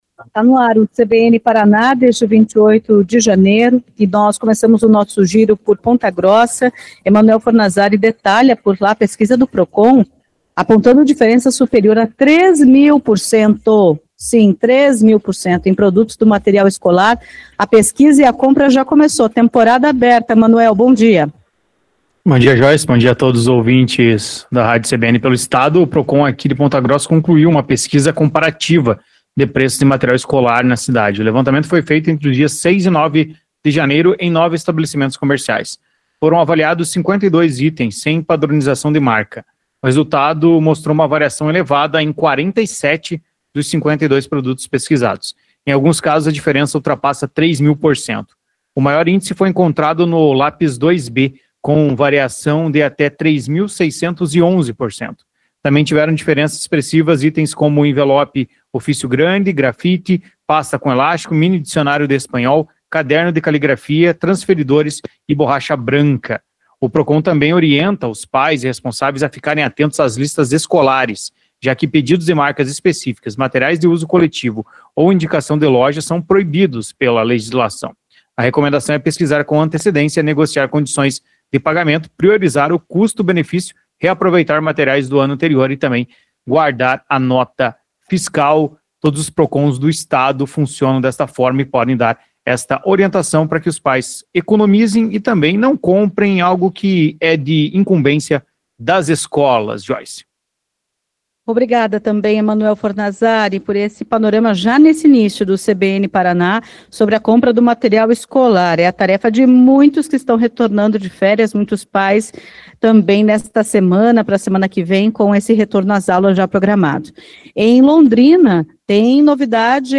O CBN Paraná vai ao ar de segunda a sexta-feira, a partir das 10h35, com participação da CBN Maringá, CBN Curitiba, CBN Londrina, CBN Cascavel e CBN Ponta Grossa.